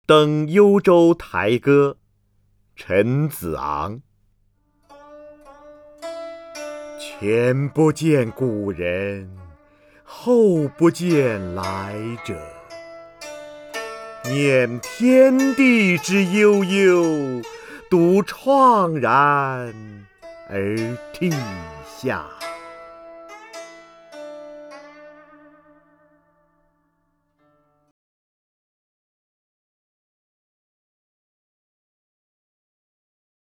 瞿弦和朗诵：《登幽州台歌》(（唐）陈子昂) （唐）陈子昂 名家朗诵欣赏瞿弦和 语文PLUS